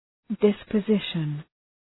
{,dıspə’zıʃən}